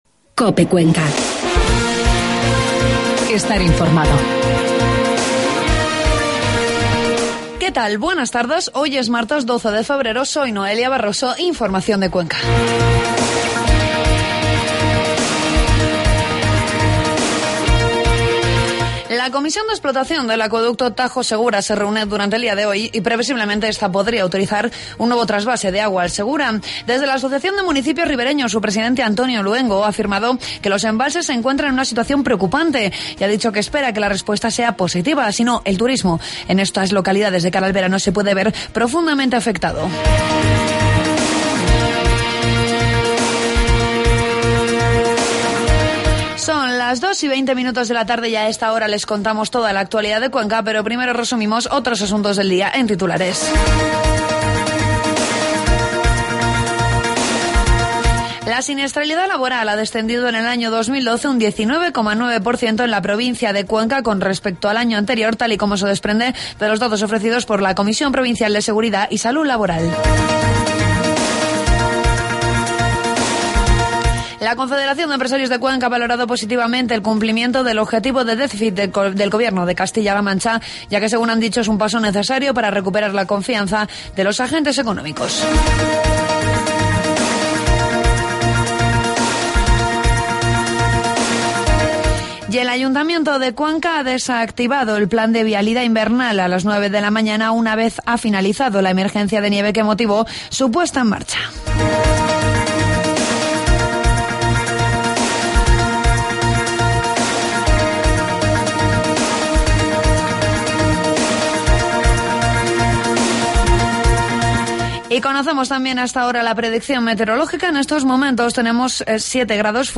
informativos de mediodía